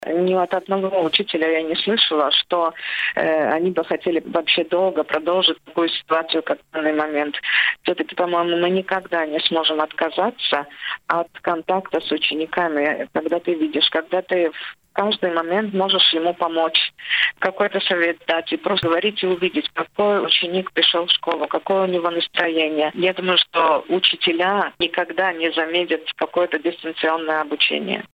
Об этом директор рассказала в эфире радио Baltkom.